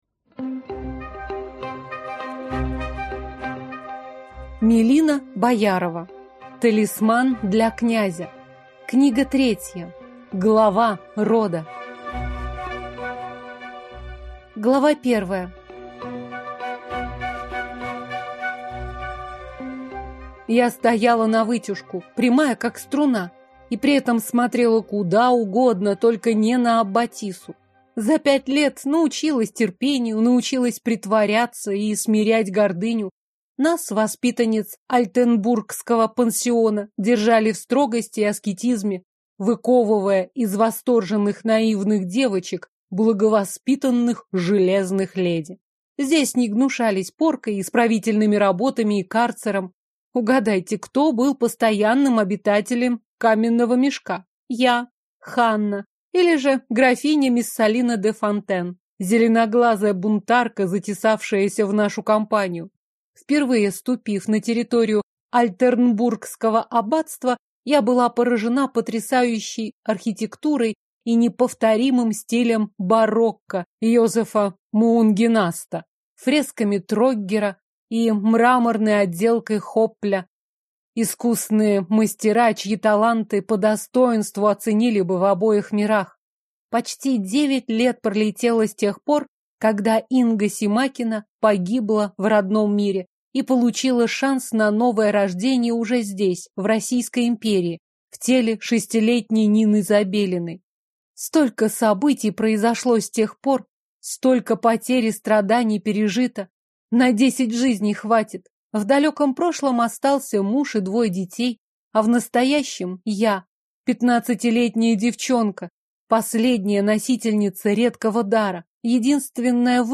Аудиокнига Талисман для князя. Глава рода | Библиотека аудиокниг